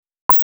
ultrasound.wav